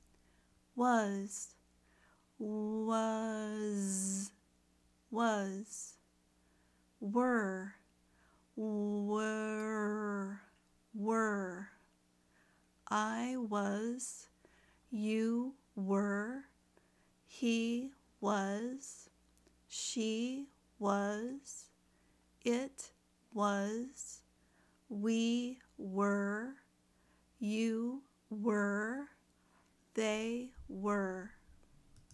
They also have irregular pronunciation. Listen and repeat.
Pronounce “Was” and “Were”